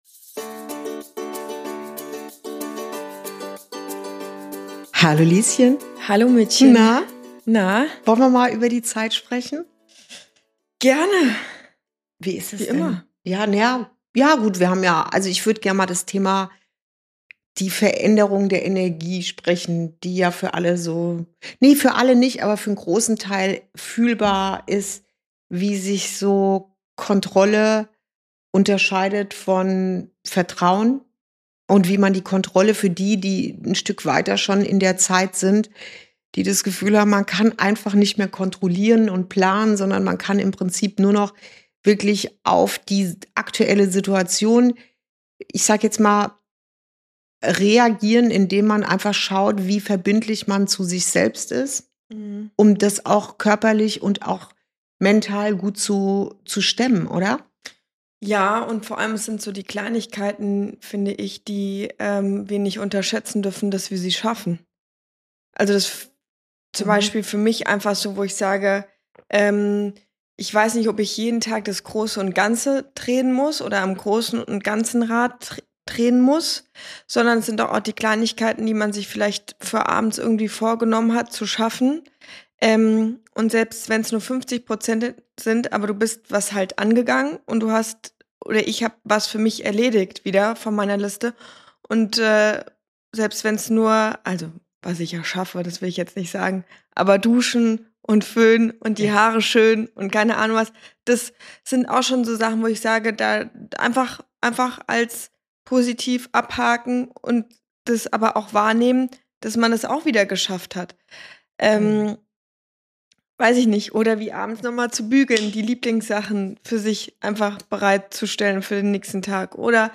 Ein Gespräch zwischen Mutter und Tochter